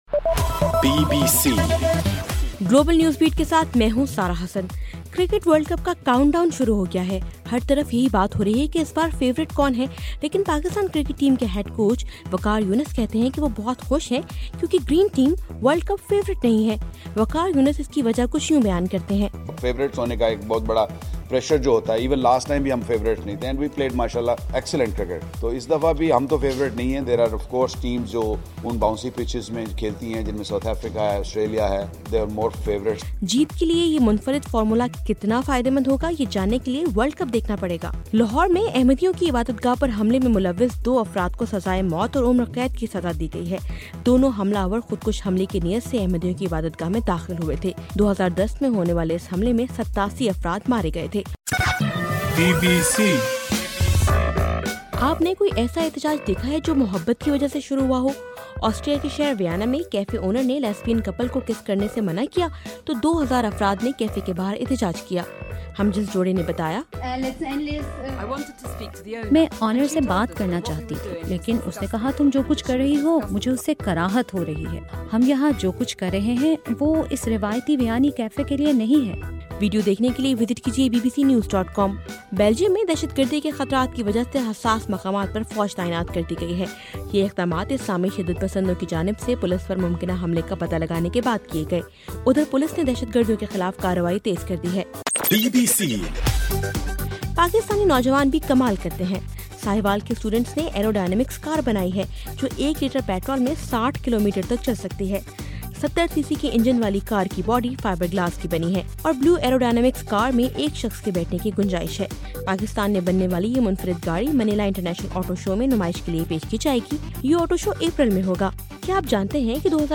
جنوری 18: رات 10 بجے کا گلوبل نیوز بیٹ بُلیٹن